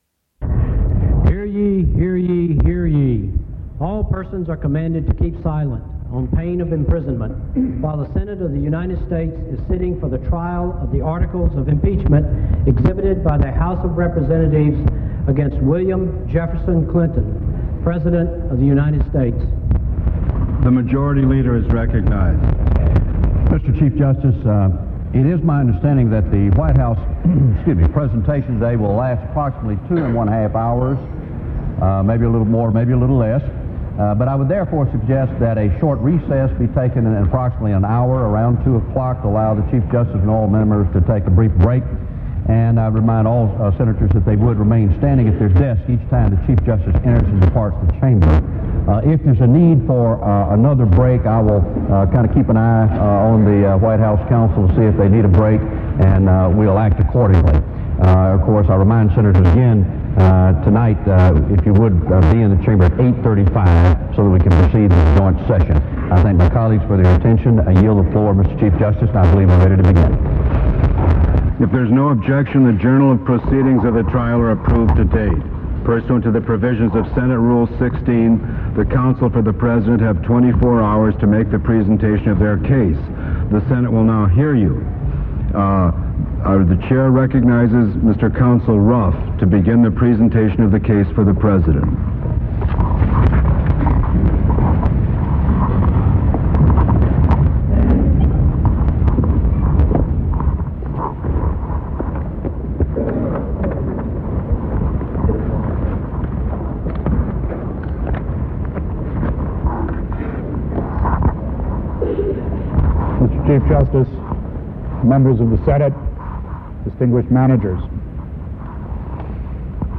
Charles Ruff, attorney for President Clinton, gives the rebuttal to previous testimony in the impeachment of President Clinton
Committee on the Judiciary Subjects Clinton, Bill, 1946- Impeachments Trials (Impeachment) United States Material Type Sound recordings Language English Extent 00:60:00 Venue Note Broadcast on C-Span 2, Jan. 19, 1999.